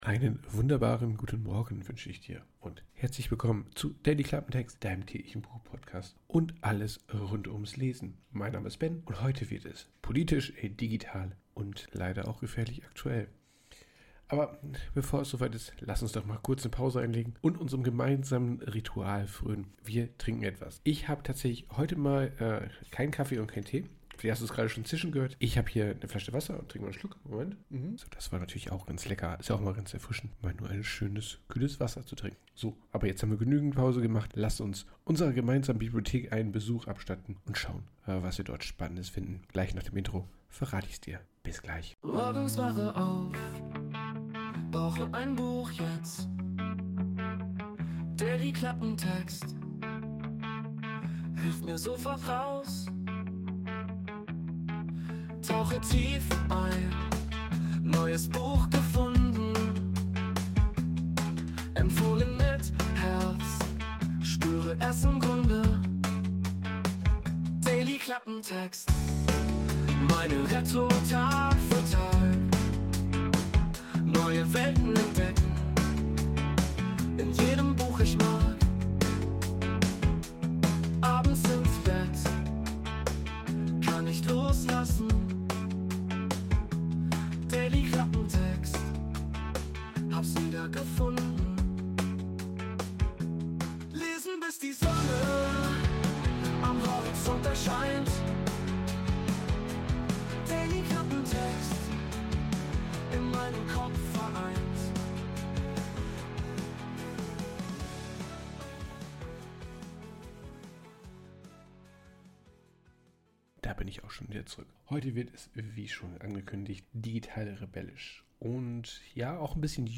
cinematic trailer intro - no copyright
Intromusik: Wurde mit der KI Suno erstellt.